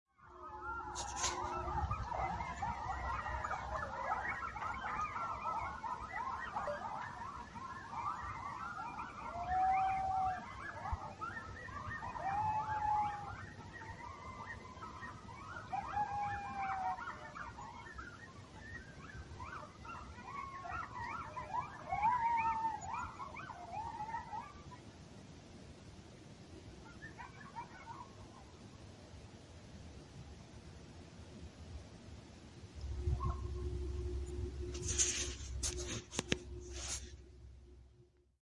Coyotes At Dawn Bouton sonore